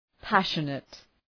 Προφορά
{‘pæʃənıt}